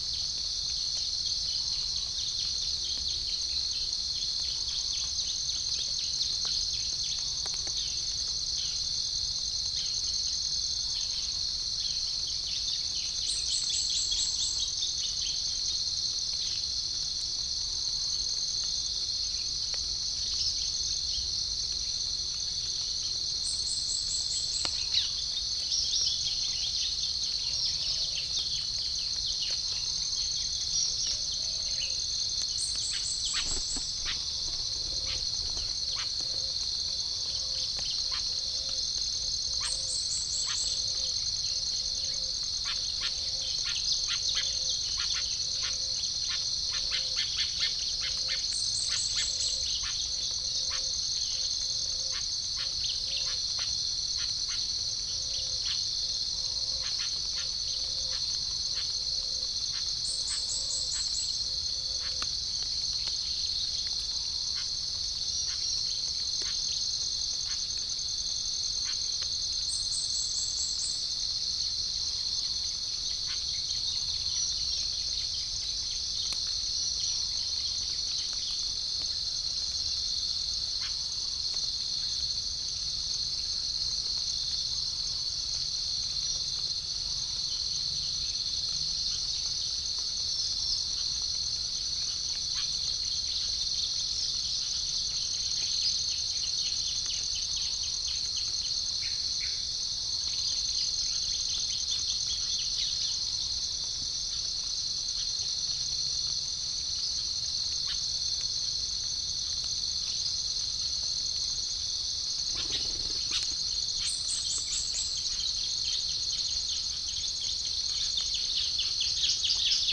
Gallus gallus
Geopelia striata
Pycnonotus aurigaster
Prinia familiaris
Dicaeum trigonostigma